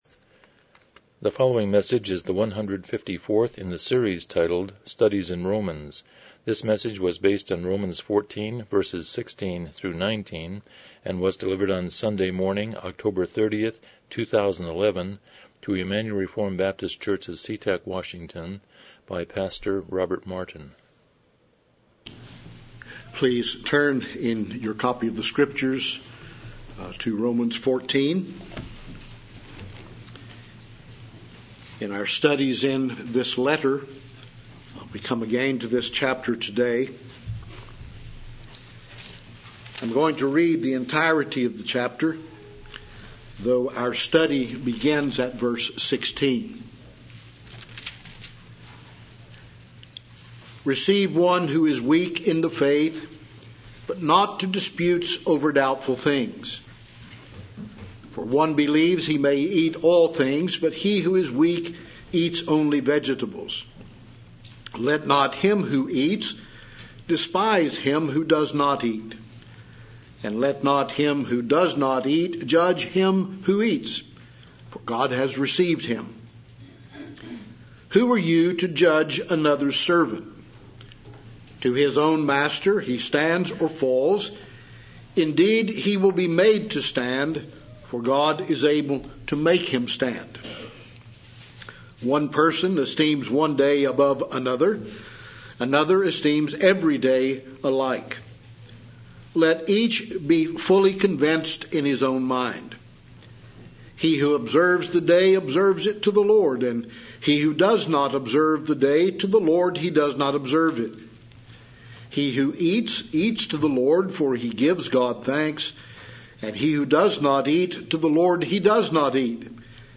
Romans 14:16-19 Service Type: Morning Worship « 132 Chapter 26.10